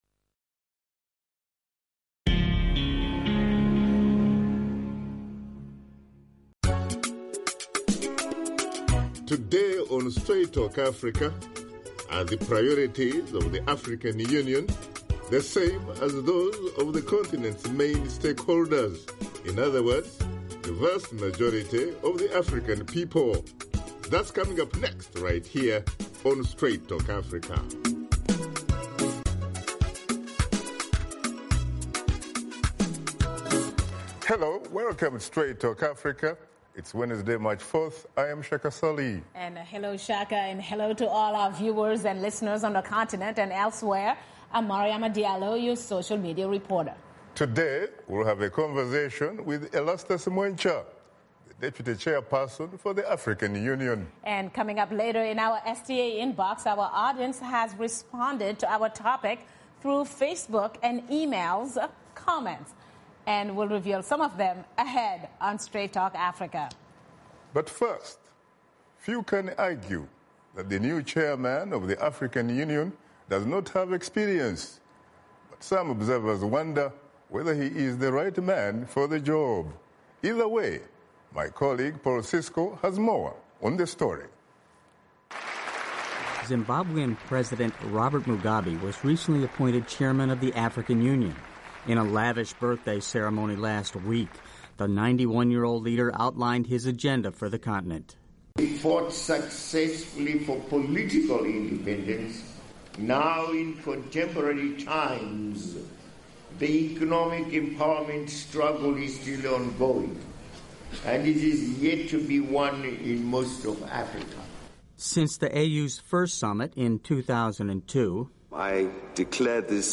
Washington Studio Guest: Erastus Mwencha, Deputy Chairperson for the African Union